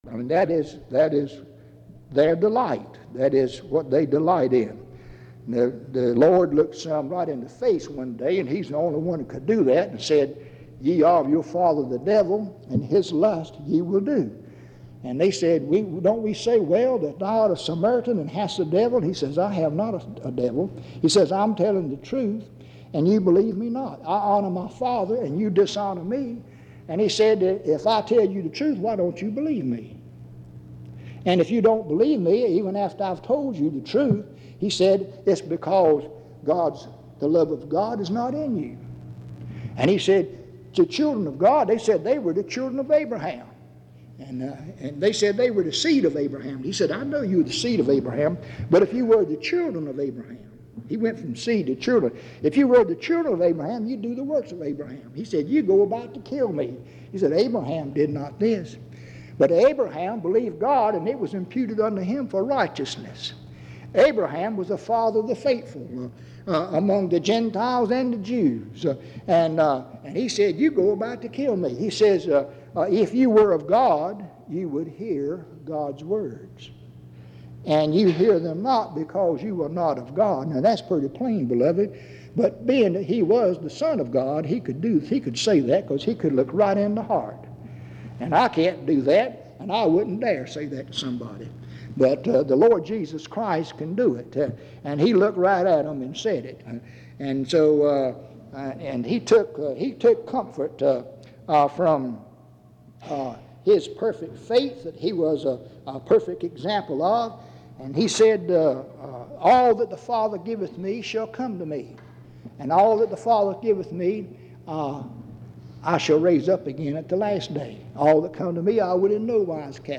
Dans Collection: Reidsville/Lindsey Street Primitive Baptist Church audio recordings La vignette Titre Date de téléchargement Visibilité actes PBHLA-ACC.001_011-A-01.wav 2026-02-12 Télécharger PBHLA-ACC.001_011-B-01.wav 2026-02-12 Télécharger